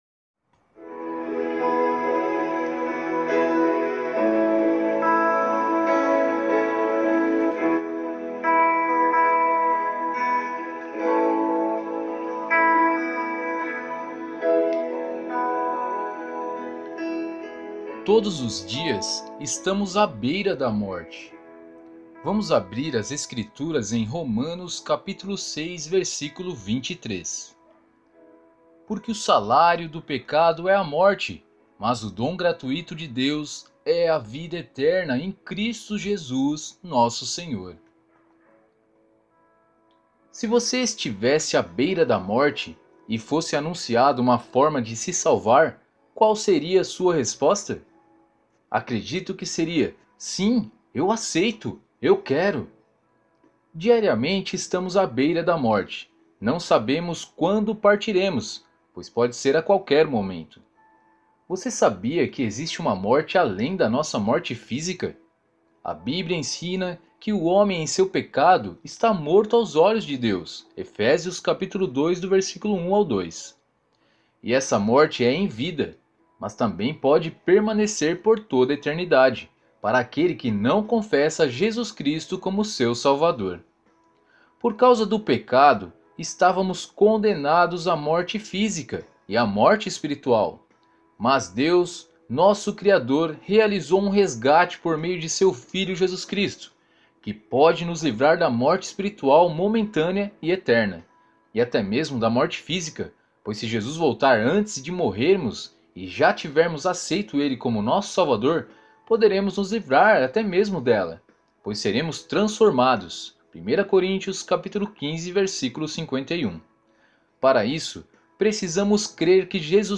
Você também pode ouvir a narração do Alimento Diário!